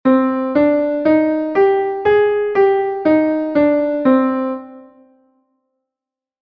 hirajoshi.mp3